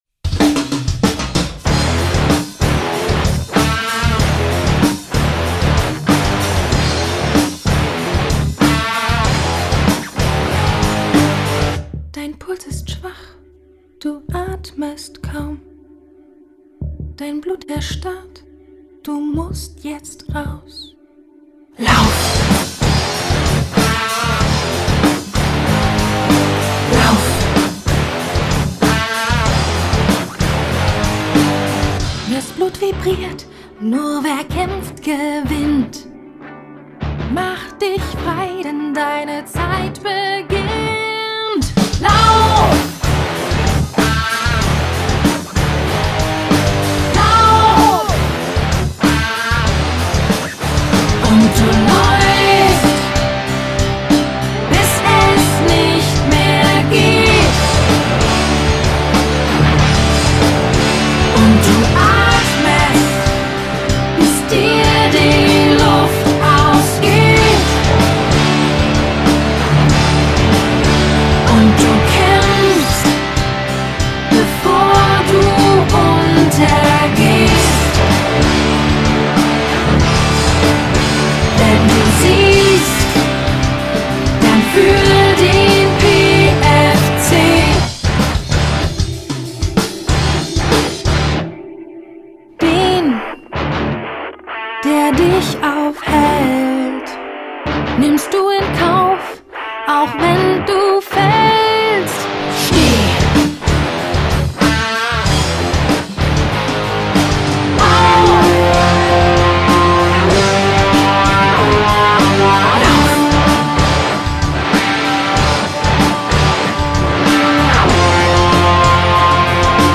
Unsere Hymne